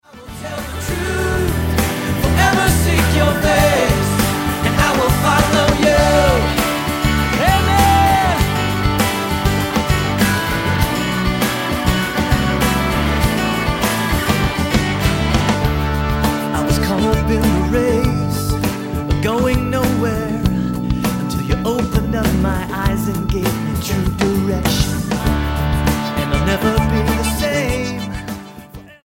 STYLE: MOR / Soft Pop
with enthusiastic praise & worship